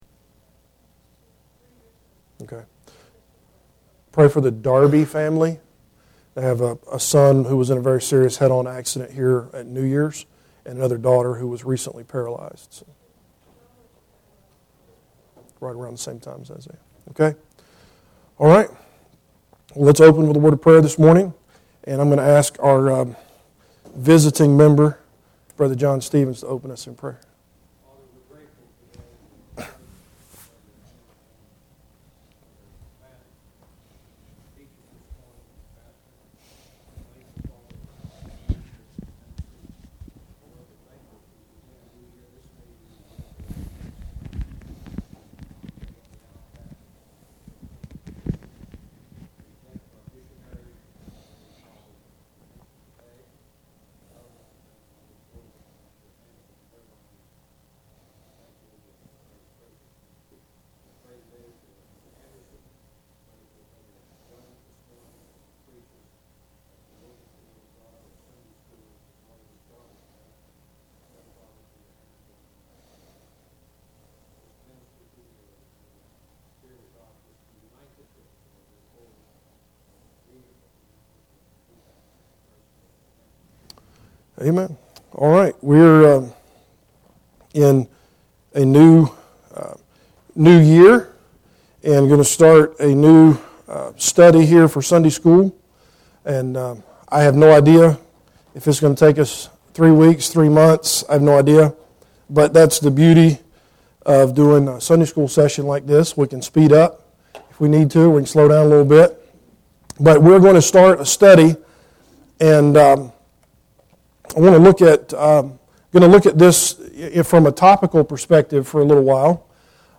John 1:18 Service Type: Adult Sunday School Class Bible Text